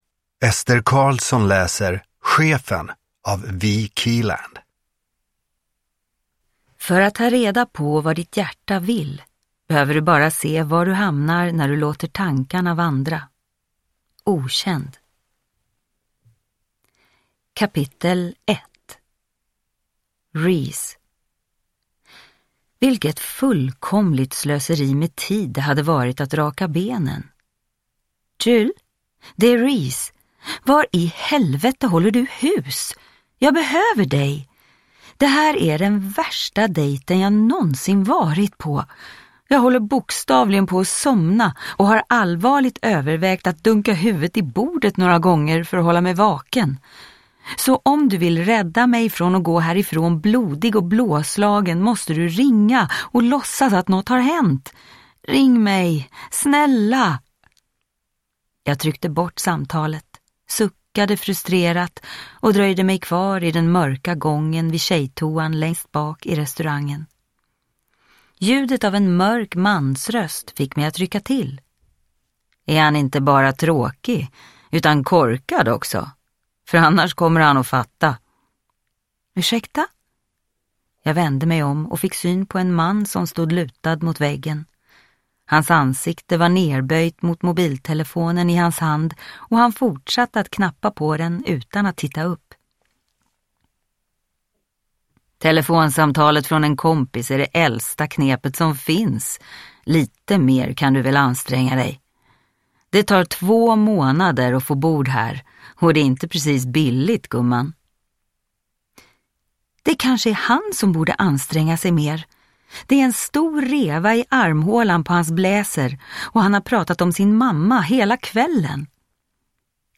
Chefen – Ljudbok – Laddas ner